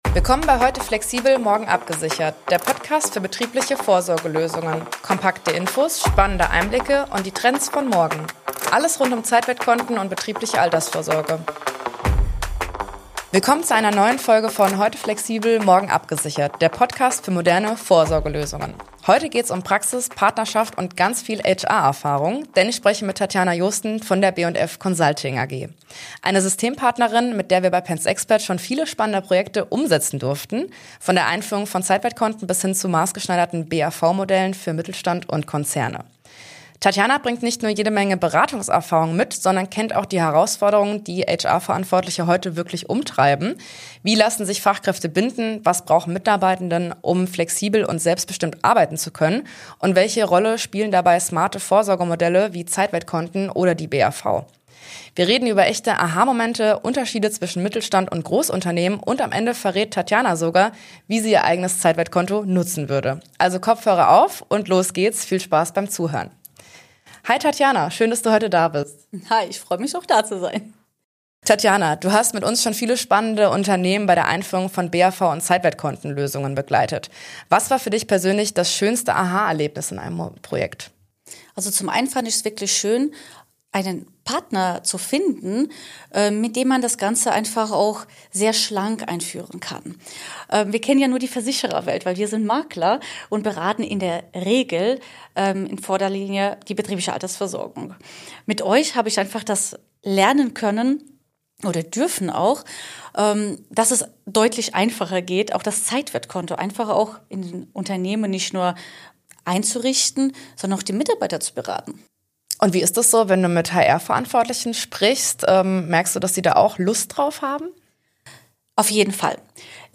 Gespräch über Zusammenarbeit, Erfahrung und moderne Vorsorge.